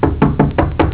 Knock (15 kb, .wav)
Knock.wav